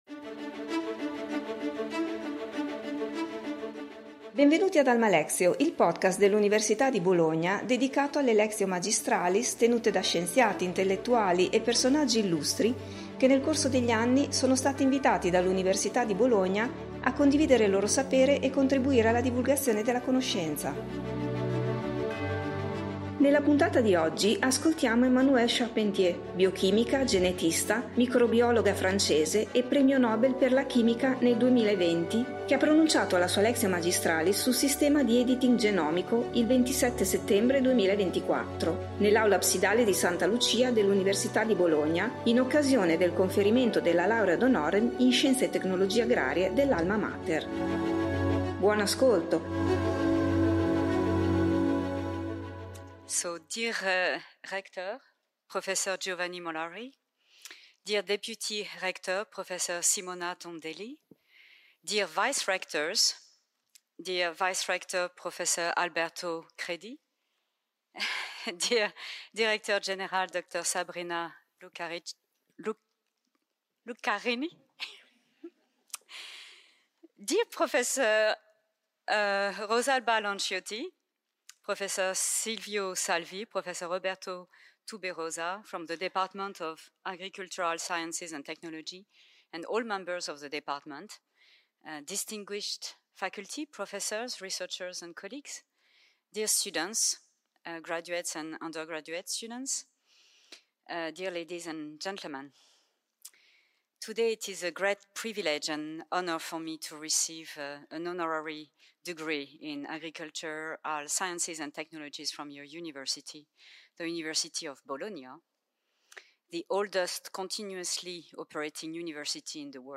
Emmanuelle Charpentier, biochimica, genetista e microbiologa francese, premio Nobel per la chimica nel 2020, ha pronunciato la sua Lectio magistralis il 27 settembre 2024 nell’Aula Absidale di Santa Lucia dell’Università di Bologna in occasione del conferimento della Laurea ad honorem in Scienze e Tecnologie Agrarie dell’Alma Mater.